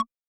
RDM_Raw_SY1-Perc03.wav